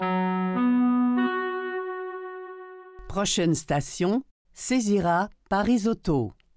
Listen to the métro voice pronounce Césira-Parisotto